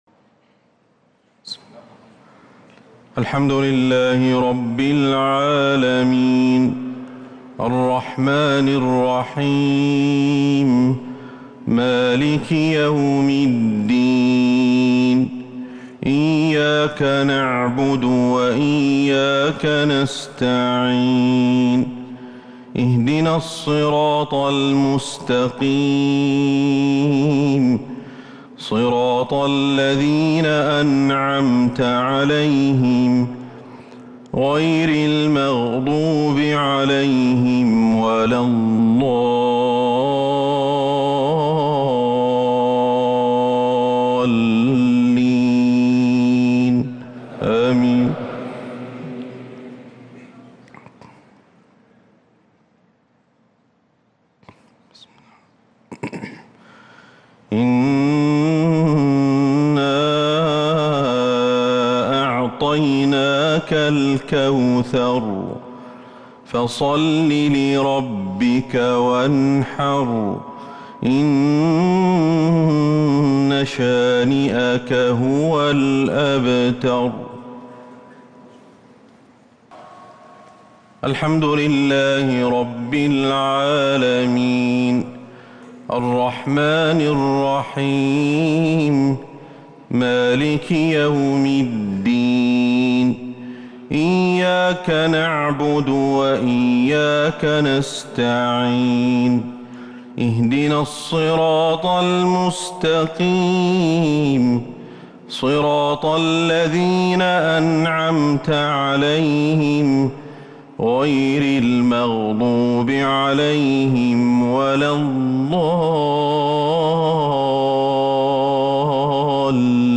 عشاء الجمعة 28 رمضان 1443هـ سورتي الكوثر و النصر |  Isha prayer from Surat kawthar and An-Nasr > 1443 🕌 > الفروض - تلاوات الحرمين